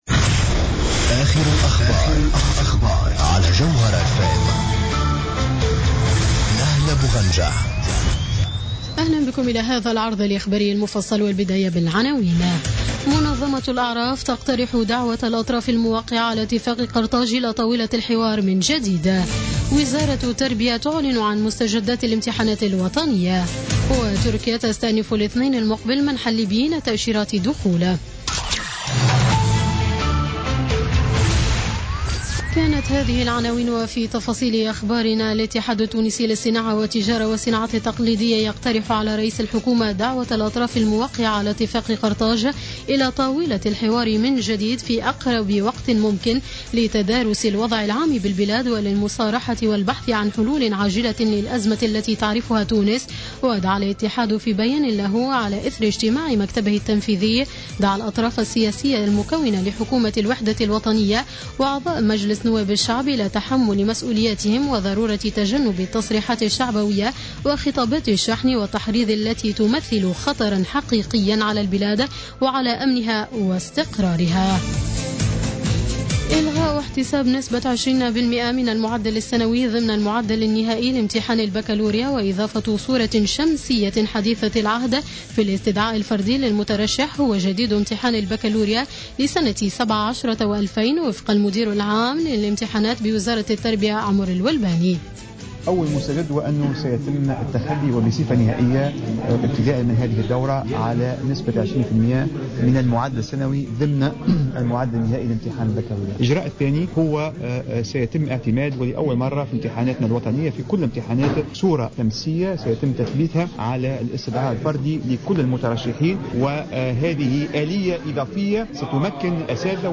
نشرة أخبار السابعة مساء ليوم الجمعة 14 أفريل 2017